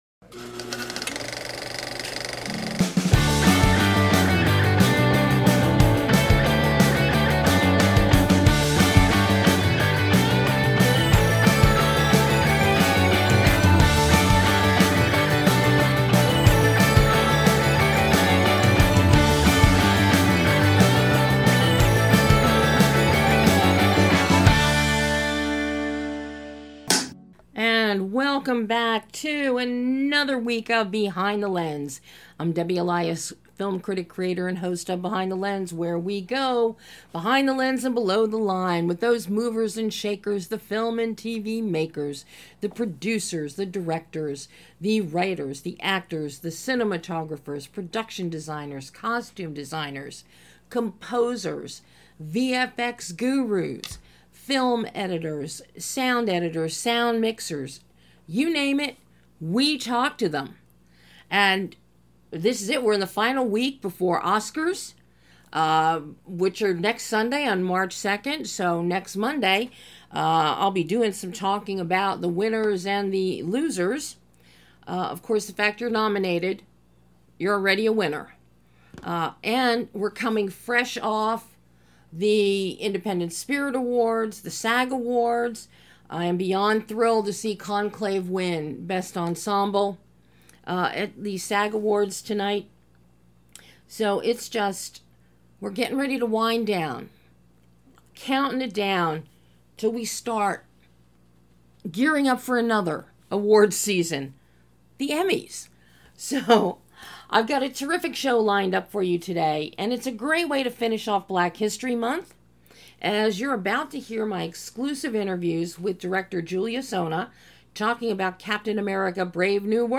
BTL Radio Show